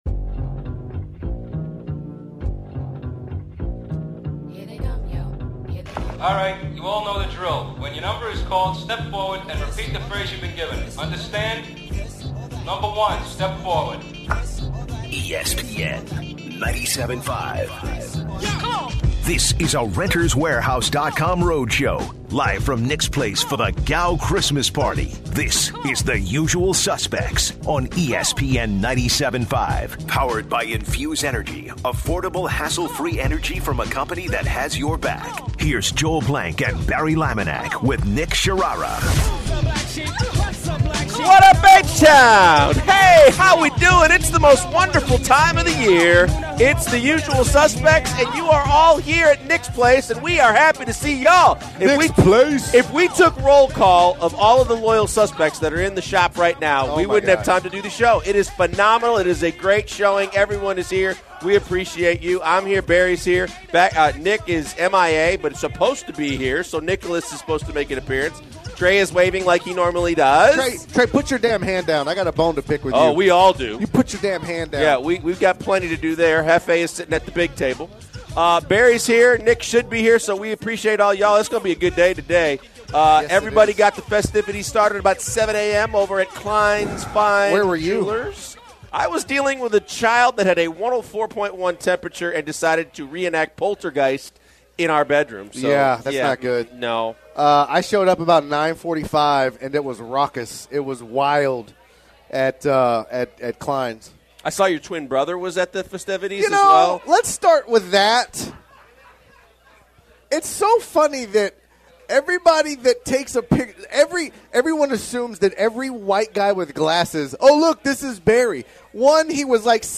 They get others at the restaurant to join in on their craziness. They actually get to some sports talk and discuss the Rockets’ loss and Chris Paul’s injury.